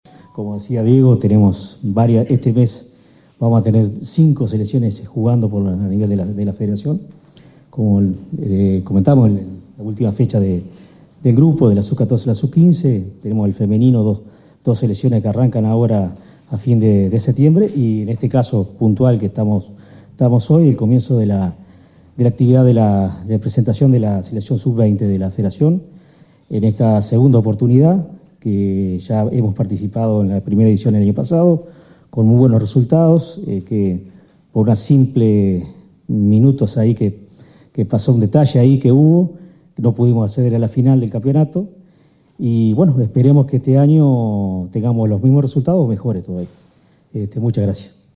En la tarde de este miércoles, en el Salón de Actos de la Intendencia de Colonia, se realizó la presentación oficial de la Selección Sub 20 de la Federación de Fútbol de Colonia, que participará en el próximo Campeonato Nacional de la categoría.